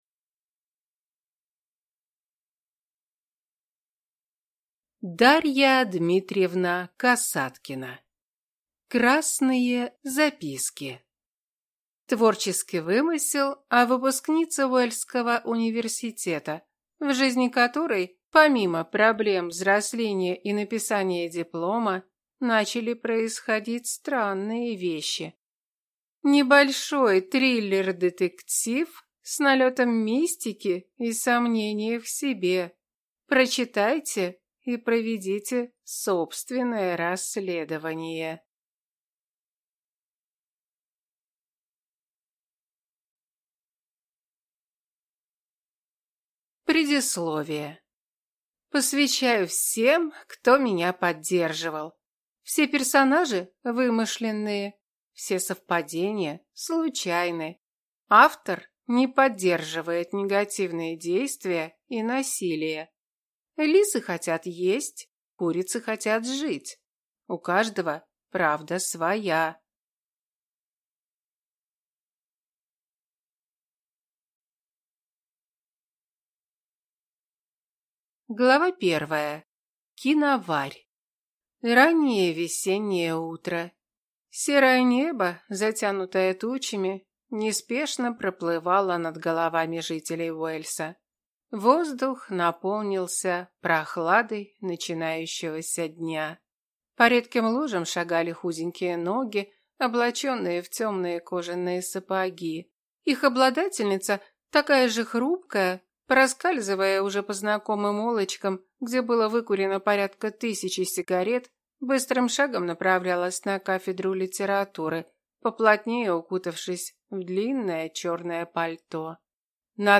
Аудиокнига Красные записки | Библиотека аудиокниг
Прослушать и бесплатно скачать фрагмент аудиокниги